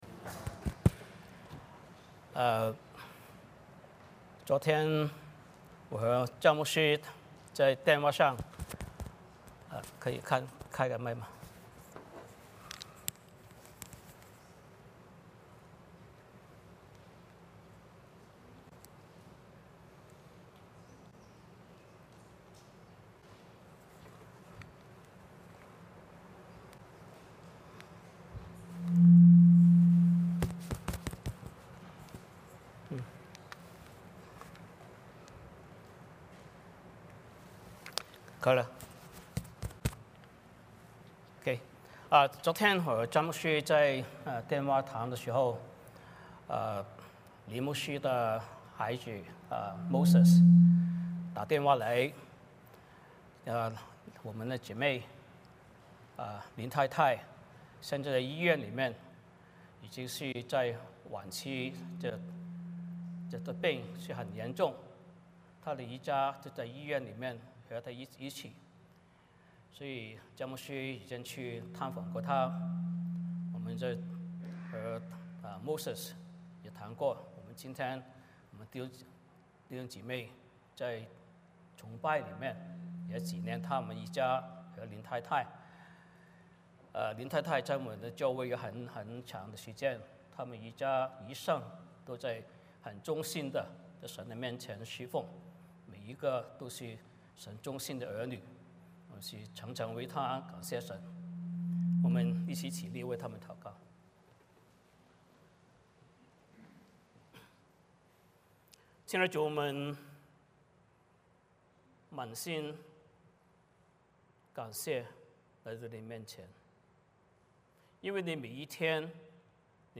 Passage: 哥林多前书 3:10-23 Service Type: 主日崇拜 欢迎大家加入我们的敬拜。